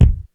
Kick 01.wav